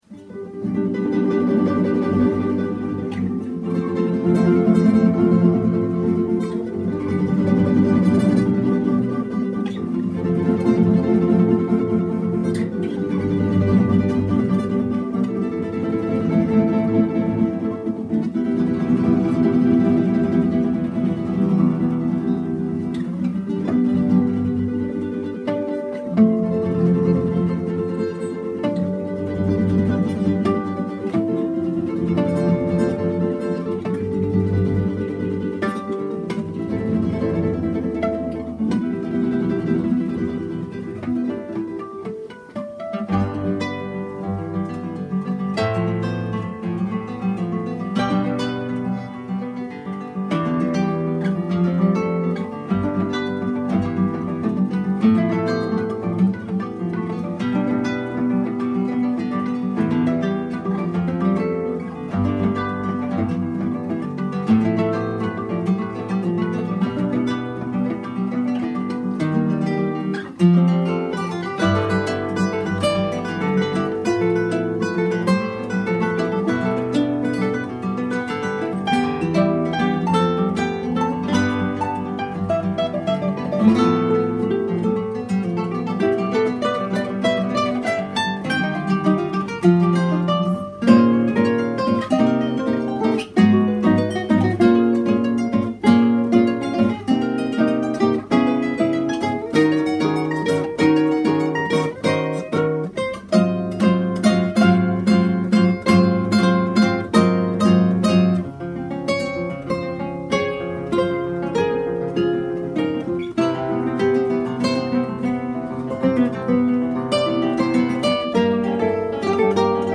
for 3 guitars